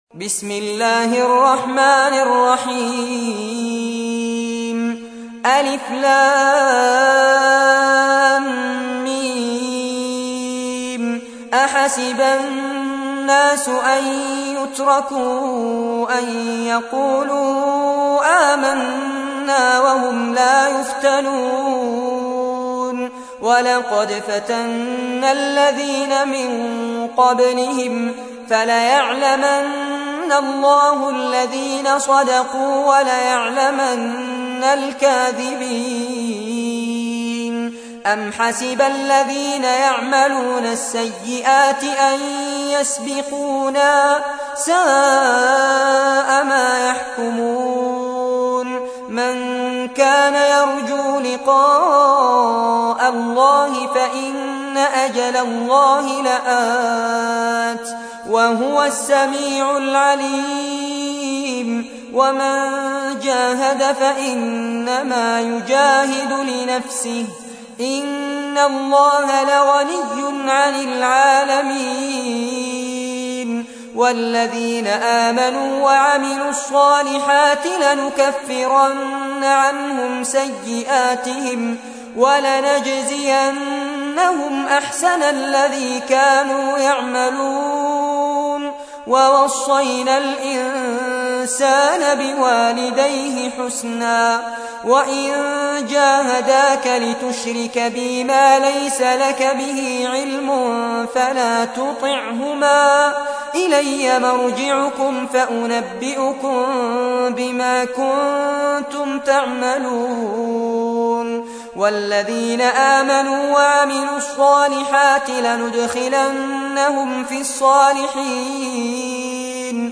تحميل : 29. سورة العنكبوت / القارئ فارس عباد / القرآن الكريم / موقع يا حسين